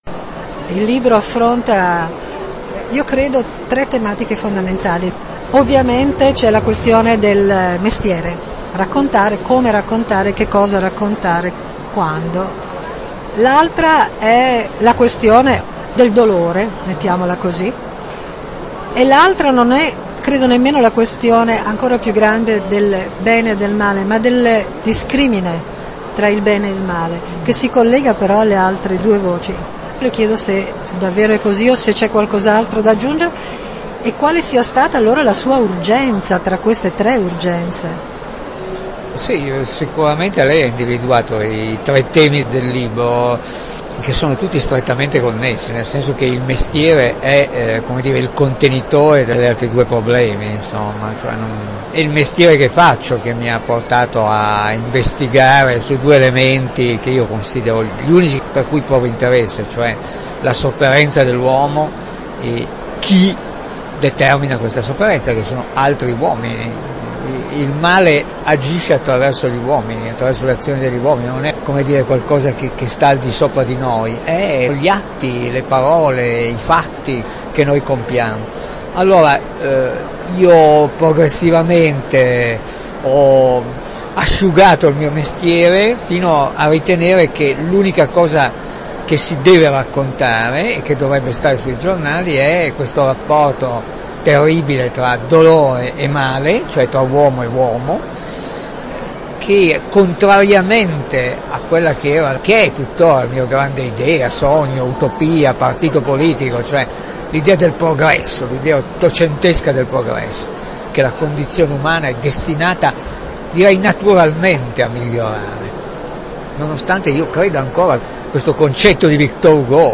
“Ombre dal fondo”: chiacchierata con Domenico Quirico